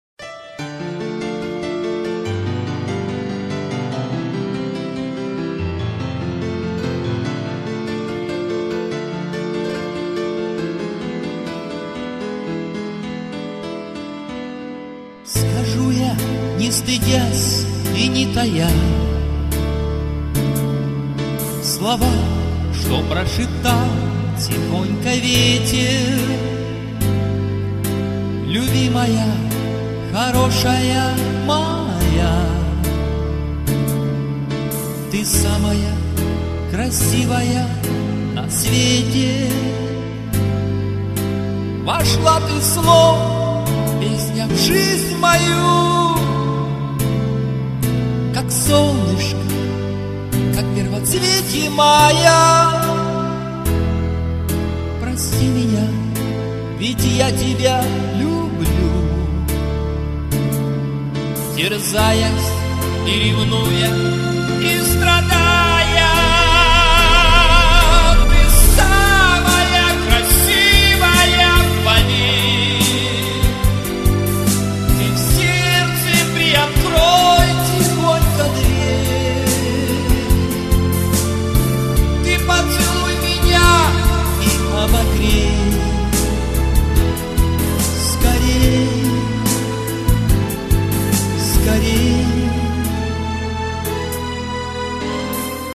Демо-версии наших песен